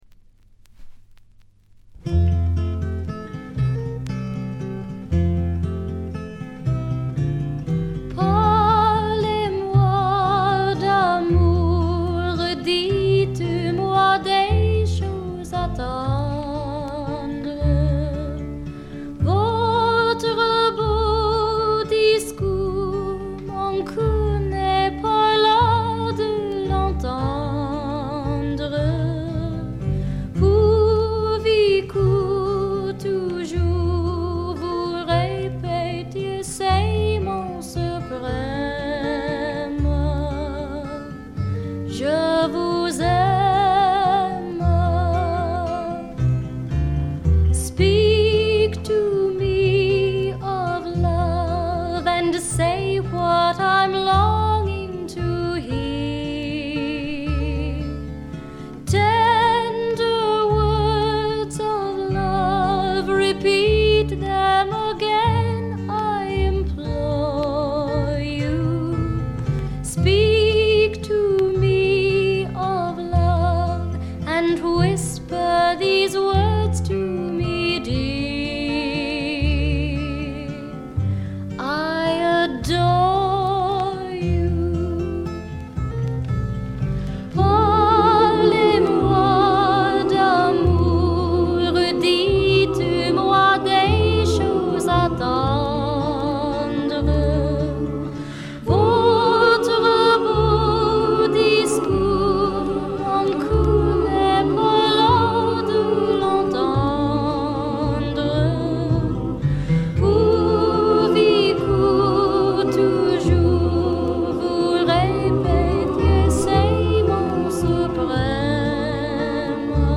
わずかなチリプチ程度。
カナダの女優／歌姫による美しいフォーク作品です。
この時点でまだ20歳かそこらで、少女らしさを残したかわいらしい歌唱がとてもよいです。
試聴曲は現品からの取り込み音源です。